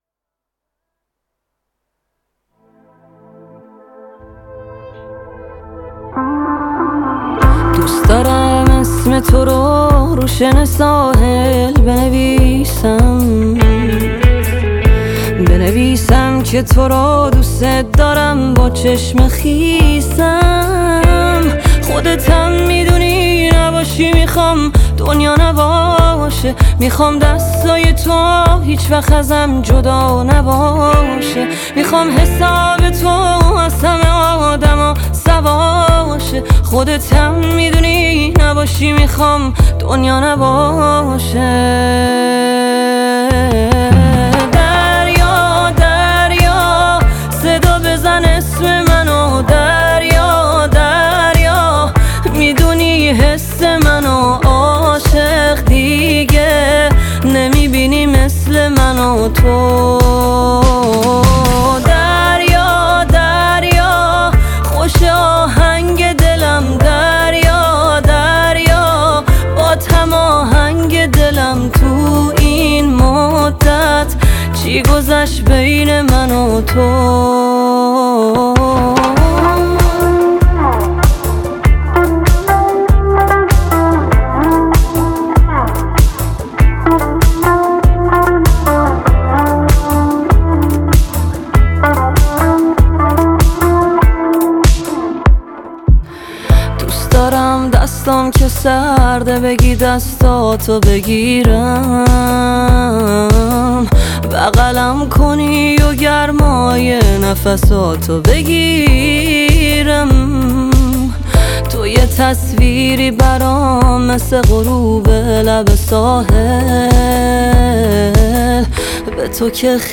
بسیار عاشقانه و جذابه
رپ